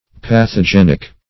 pathogenic - definition of pathogenic - synonyms, pronunciation, spelling from Free Dictionary
Pathogenic \Path`o*gen"ic\, a. [Gr. pa`qos disease + the root of